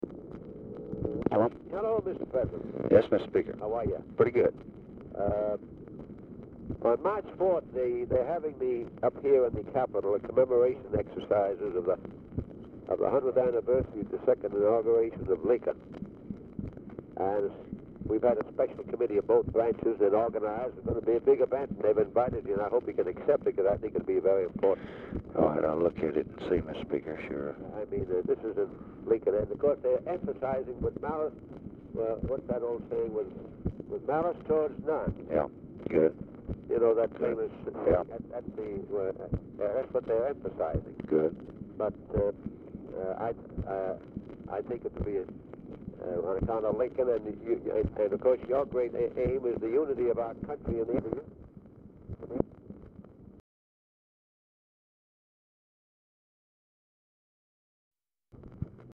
Telephone conversation
Dictation belt